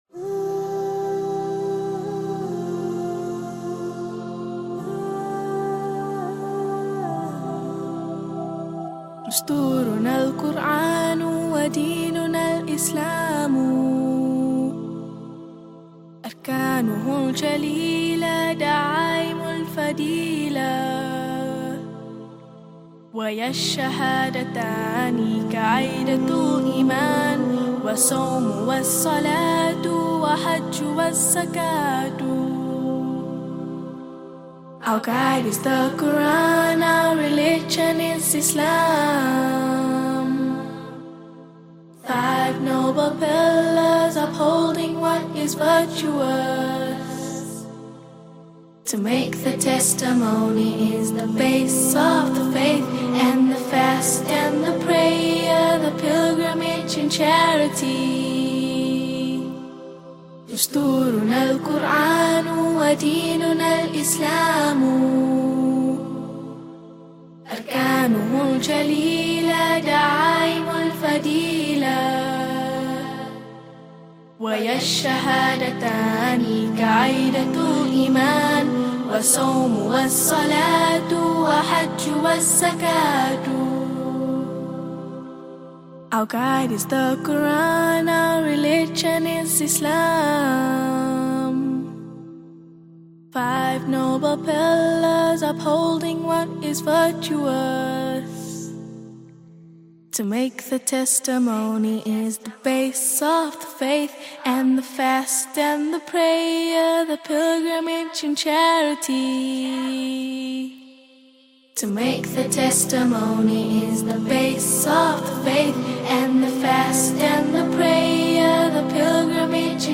This heartfelt nasheed expresses the deep love and devotion between a husband and wife in Islam, rooted not in fleeting emotion but in faith, good deeds, and the consciousness of Allah.